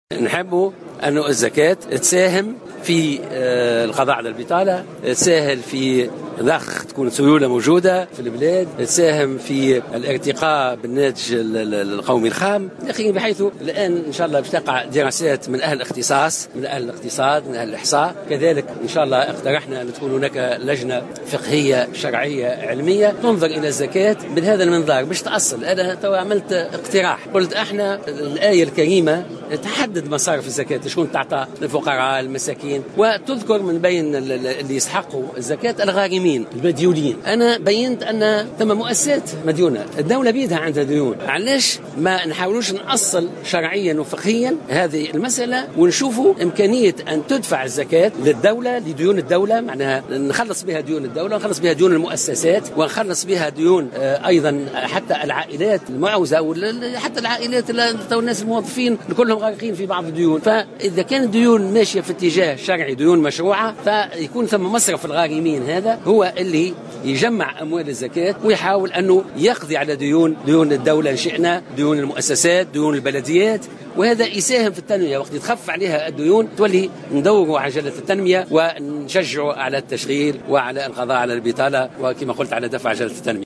قال وزير الشؤون الدينية محمد خليل في تصريح لمراسل الجوهرة "اف ام" اليوم الأربعاء 20 أفريل 2016 أن الزكاة يمكن أن تساهم في القضاء على البطالة وضخ السيولة والارتقاء بالناتج القومي الخام مؤكدا أنه سيتم دراسة هذه المسالة من قبل أهل الاختصاص وتقع دراسات من أهل الاختصاص وأهل الإقتصاد و الإحصاء.